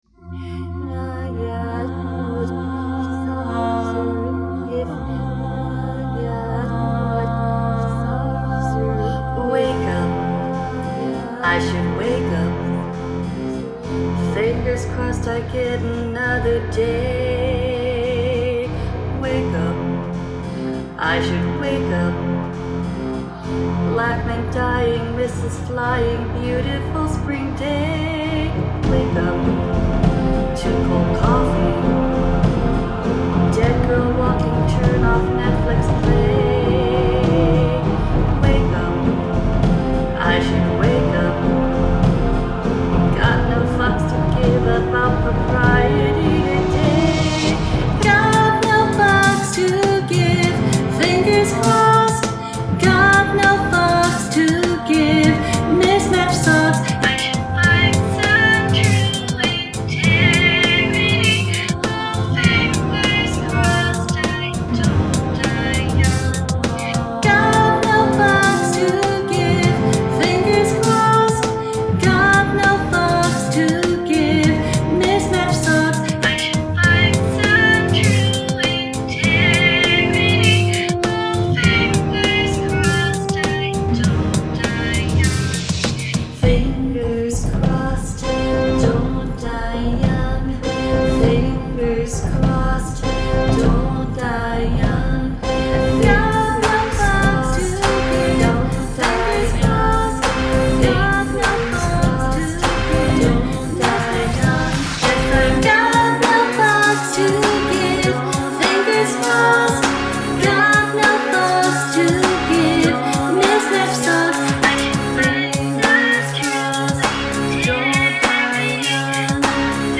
include a section of backwards singing or instrumentation